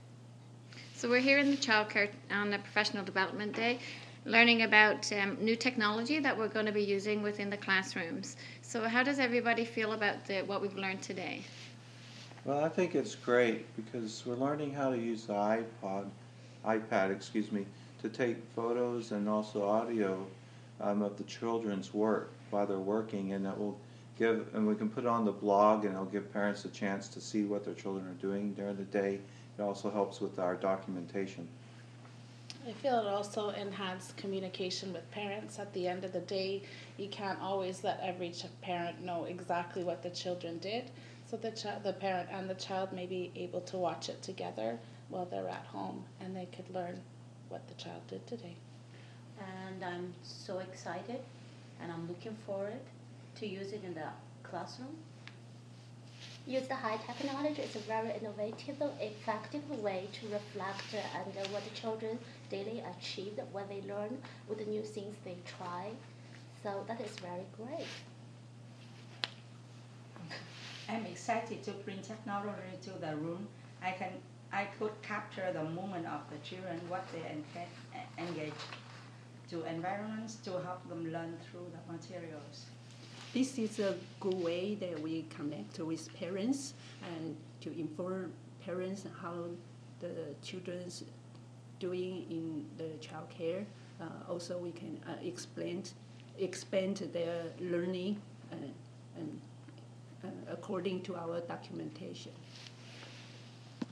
Teachers talking about using iPads